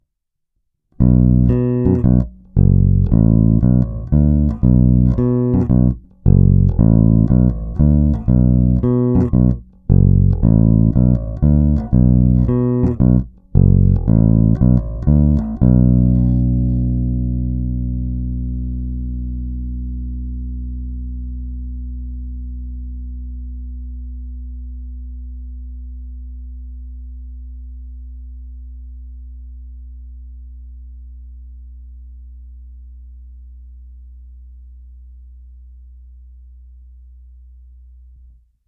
Zvuk má modernější projev, je pěkně tučný, se sametovými nižšími středy, ovšem taky je nepatrně zastřený díky použitým humbuckerům.
Není-li uvedeno jinak, následující nahrávky jsou provedeny rovnou do zvukové karty, s plně otevřenou tónovou clonou a na korekcích jsem trochu přidal jak basy, tak výšky. Nahrávky jsou jen normalizovány, jinak ponechány bez úprav.
Snímač u krku